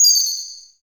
Index of /90_sSampleCDs/Optical Media International - Sonic Images Library/SI2_SI FX Vol 7/SI2_Gated FX 7